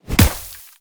Sfx_creature_pinnacarid_hop_fast_04.ogg